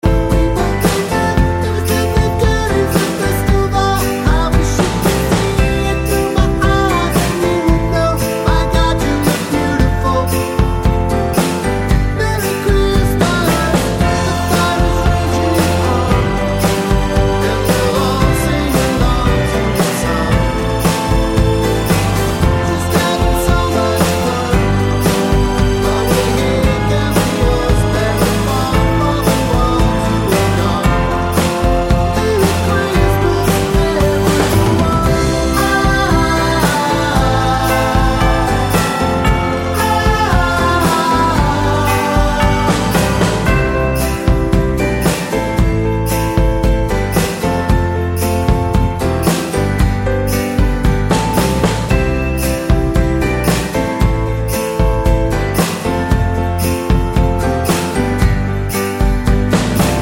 For Solo Singer Christmas 3:27 Buy £1.50